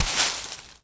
Broom Sweeping
sweeping_broom_leaves_stones_10.wav